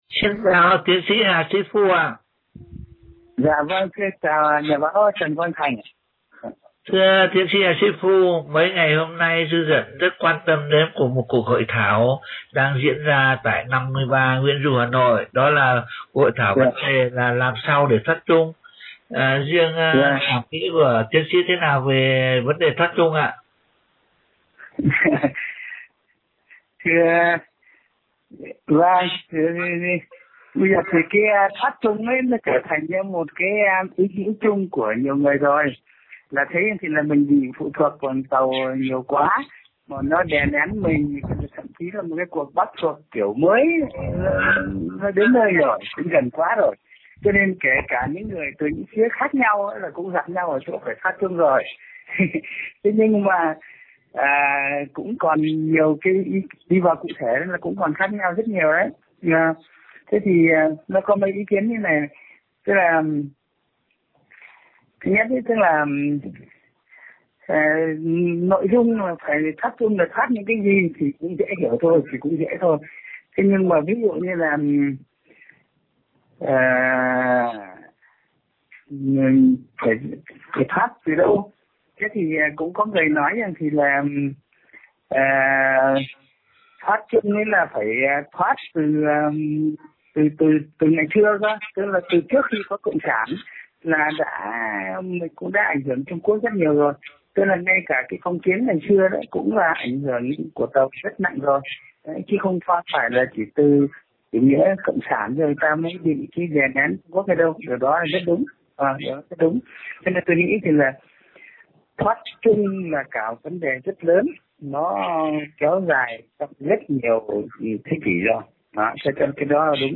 phỏng vấn